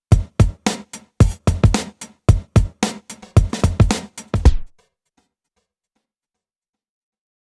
Grid, Spring and Delay keep resounding, but Mother doesn’t. It might be an effect you’re looking for, but 99% of the time I’d like my reverb to keep reverberating after punching in.